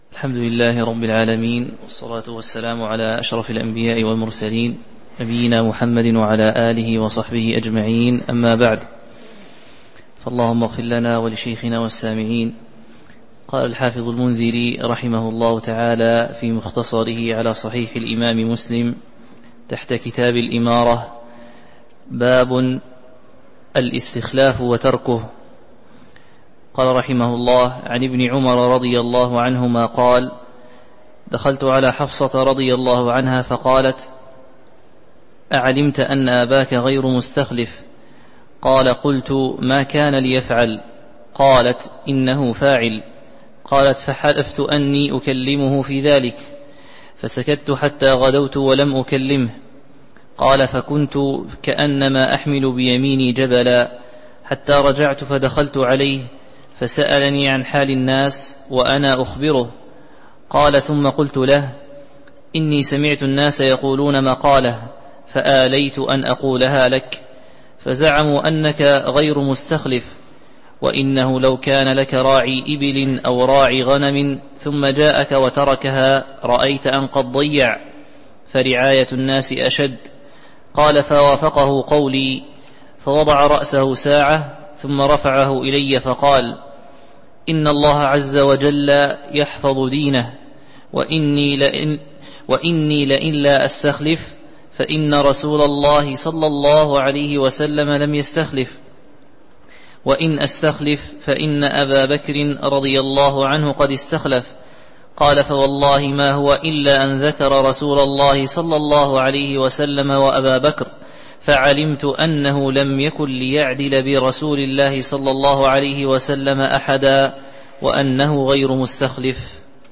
تاريخ النشر ١٧ جمادى الأولى ١٤٤٣ هـ المكان: المسجد النبوي الشيخ